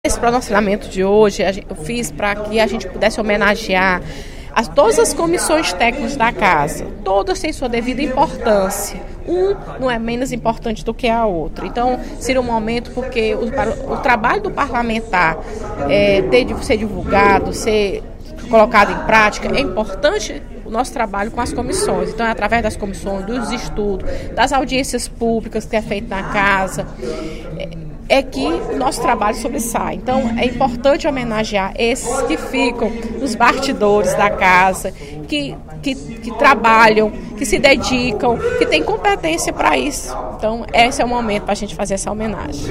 A deputada Fernanda Pessoa (PR) comunicou, nesta sexta-feira (14/12), durante o primeiro expediente, que na próxima segunda-feira (17) será realizada na Casa uma sessão solene para enaltecer o trabalho prestado pelos servidores das comissões técnicas.